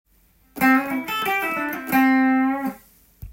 混ぜたギターフレーズ集
エリッククラプトンなどのブルース系ギタリストが使用する
AマイナーペンタトニックスケールにAメジャーペンタトニックスケールを混ぜたフレーズ。